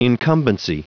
Prononciation du mot incumbency en anglais (fichier audio)
Prononciation du mot : incumbency